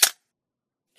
Free UI/UX sound effect: Message Received.
372_message_received.mp3